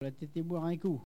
Elle provient de Bouin.
Catégorie Locution ( parler, expression, langue,... )